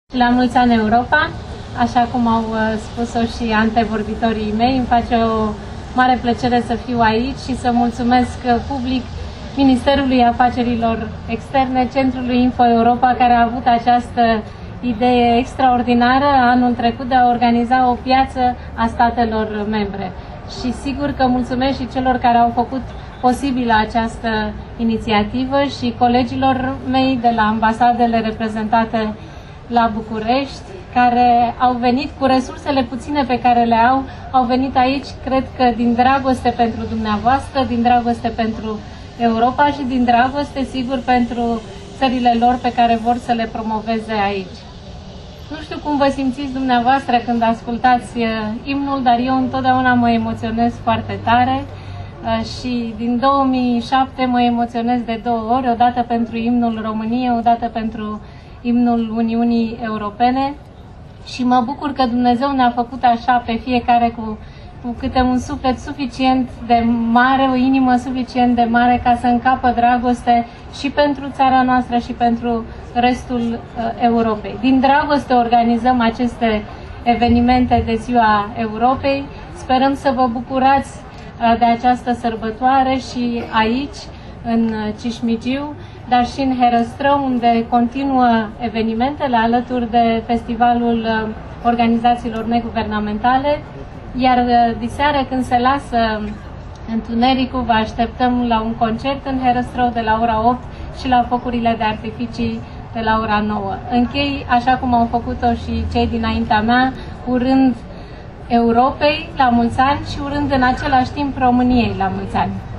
Primarul General Sorin Oprescu a sărbătorit Ziua Europei, împreună cu ministrul afacerilor externe Bogdan Aurescu, cu șefa Reprezentanței Comisiei Europene la București Angela Filote și cu ambasadori ai statelor membre UE la București, in Piața Statelor Uniunii Europene.
Sefa Reprezentantei Comisiei Europene la Bucuresti Angela Filote: